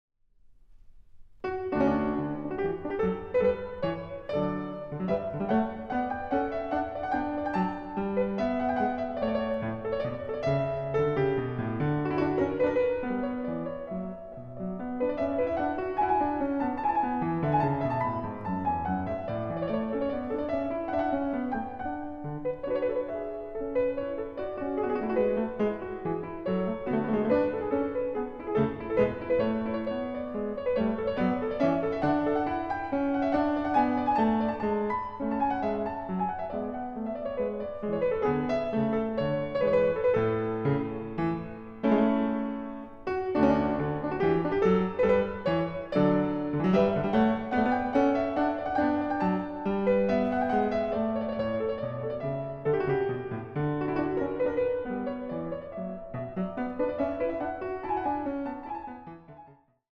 Piano
Recording: Großer Saal, Gewandhaus Leipzig, 2025